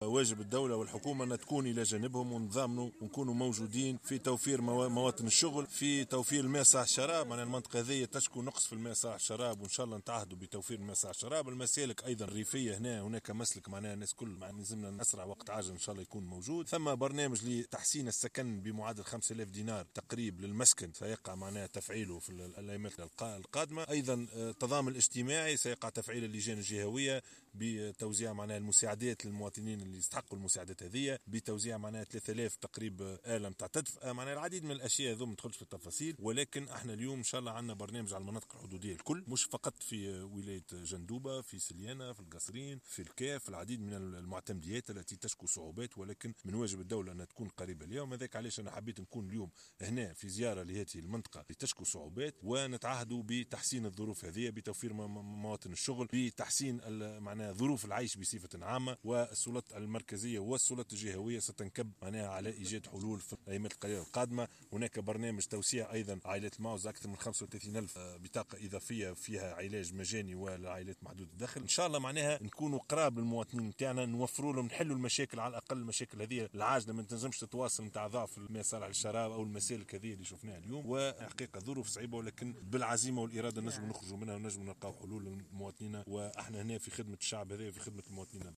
تعهّد رئيس الحكومة يوسف الشاهد، خلال زيارة غير معلنة أداها اليوم السبت 5 جانفي 2019، إلى منطقة مشراوة بحمام بورقيبة من معتمدية عين دراهم بولاية جندوبة، بتوفير الماء الصالح للشراب ومواطن شغل وتحسين ظروف العيش في المنطقة.